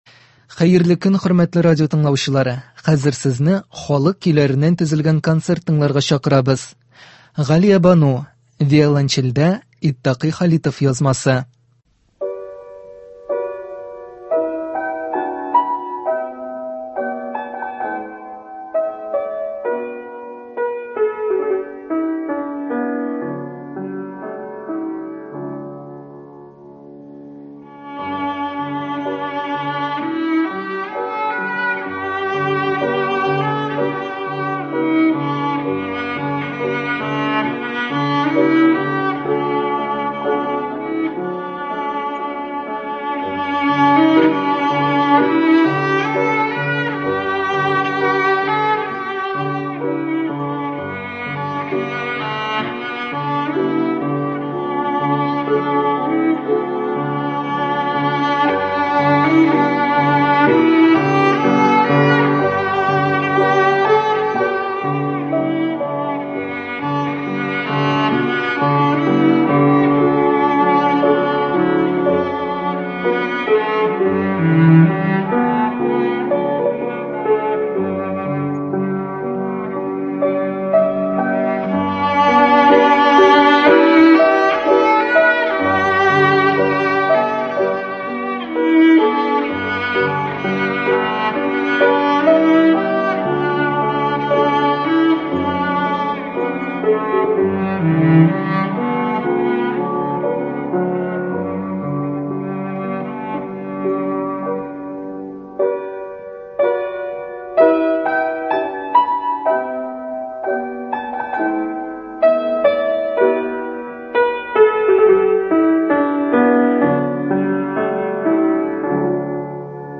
Концерт.
Татар халык көйләре. 25 апрель.